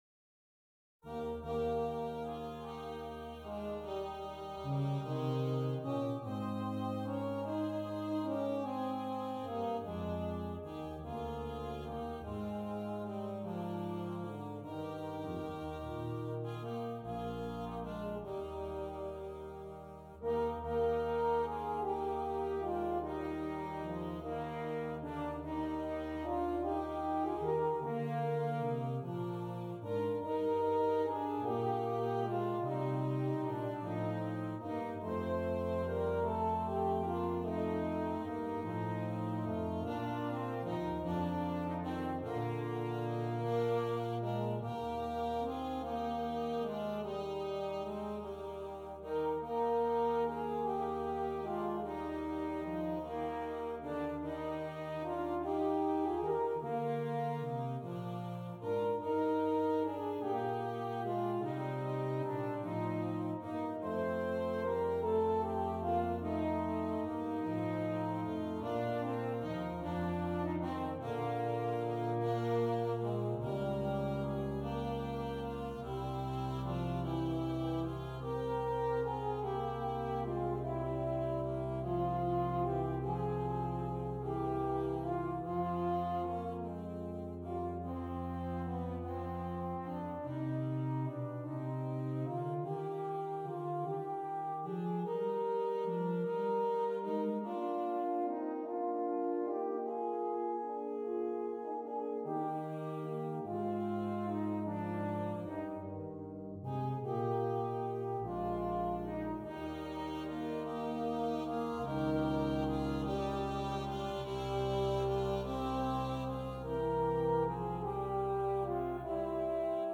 Brass Quintet
This beautiful aria is a horn feature.